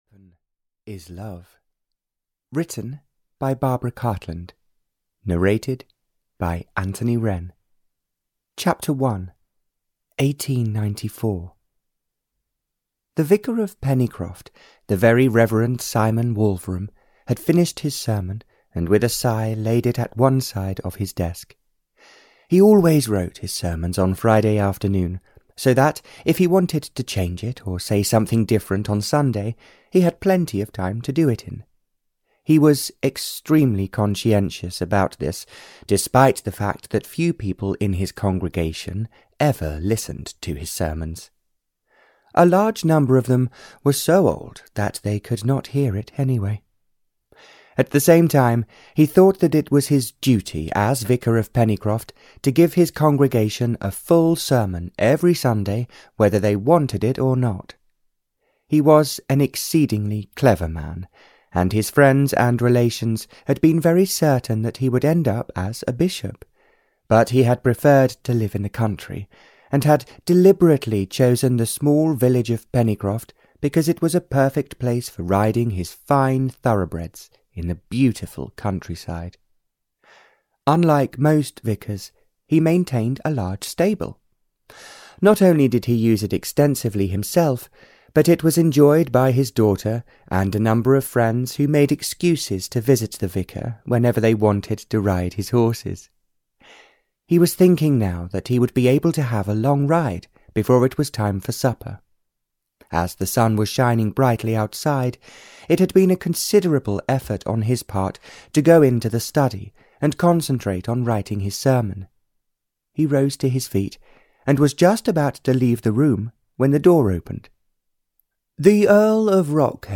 The Weapon is Love (EN) audiokniha
Ukázka z knihy